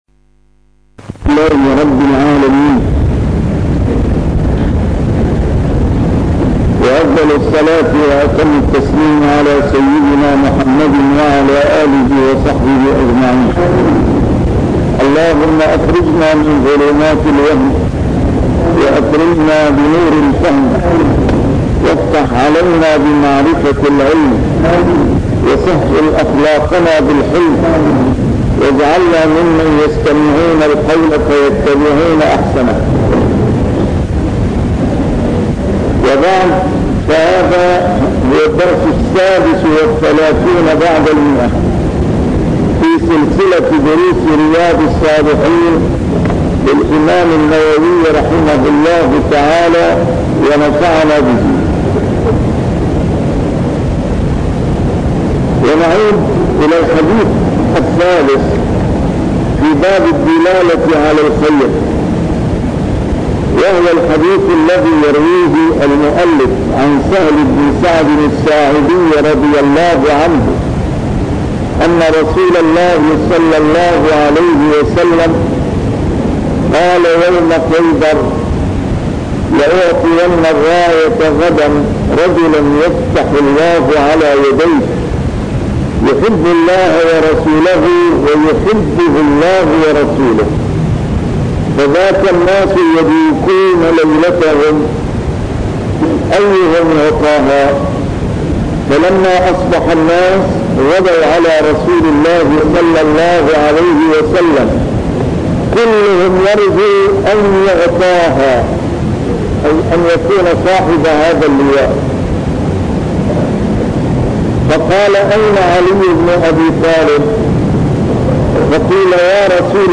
شرح كتاب رياض الصالحين - A MARTYR SCHOLAR: IMAM MUHAMMAD SAEED RAMADAN AL-BOUTI - الدروس العلمية - علوم الحديث الشريف - 236- شرح رياض الصالحين: الدّلالة على خير